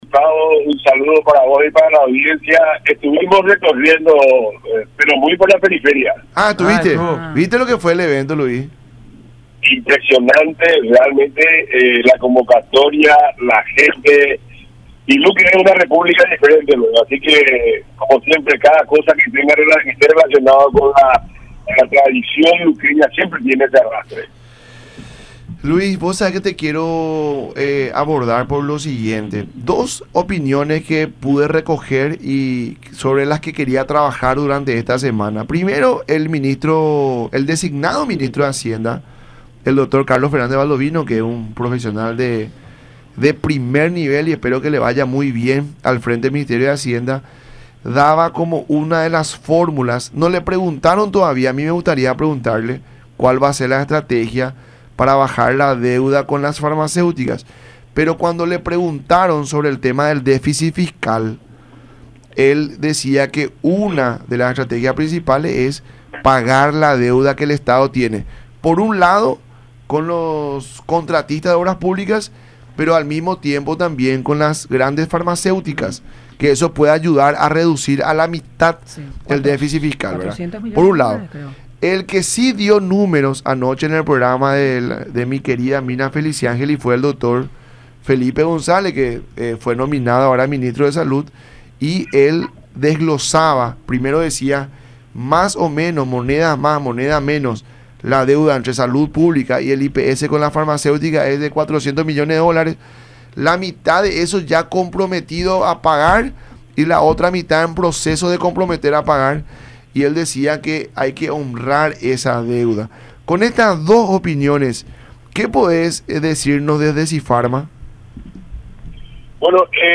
en charla con “La Mañana de Unión” por Unión TV y Radio La Unión.